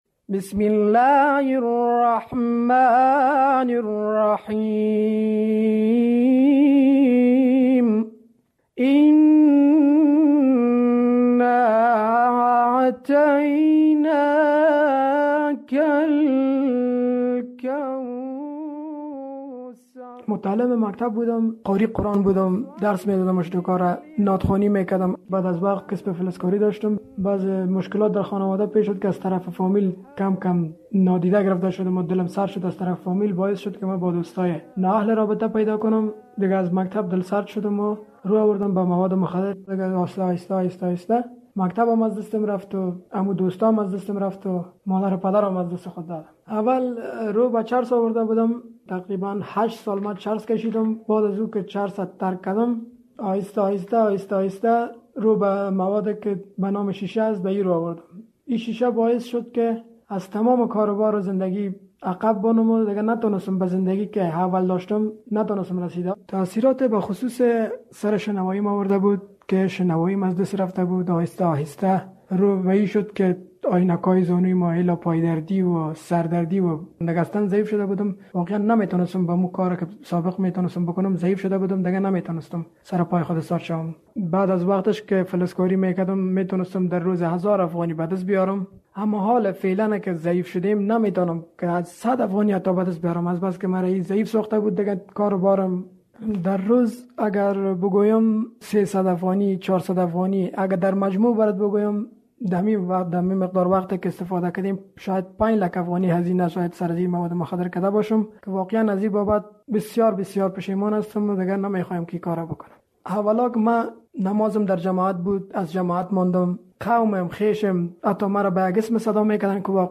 وی در مصاحبه با همکار ما چگونگی رو آوردنش به مواد مخدر و مشکلاتی را قصه کرده که در زمان اعتیاد با آن روبرو شده است.